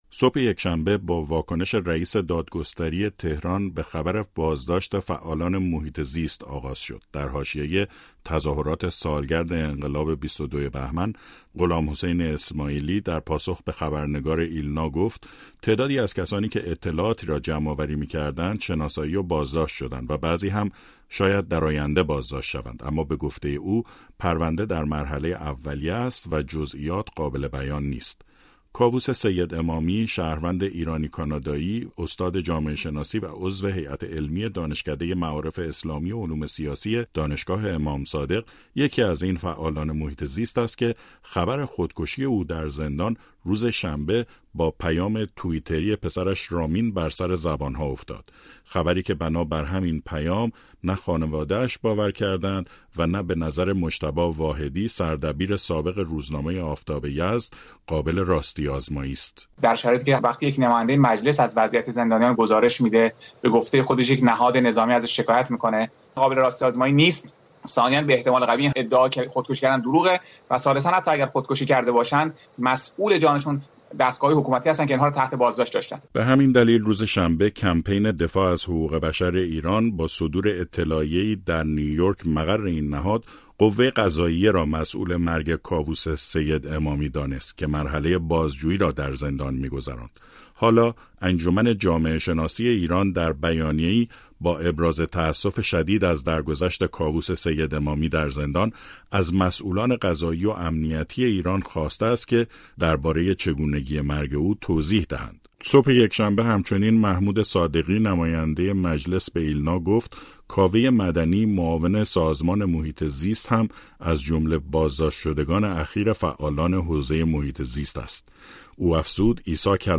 گزارش رادیو فردا درباره بازداشت فعالان محیط‌زیست